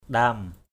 /ɗa:m/